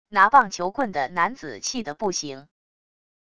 拿棒球棍的男子气得不行wav音频